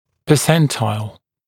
[pəˈsentaɪl][пэˈсэнтайл]процентиль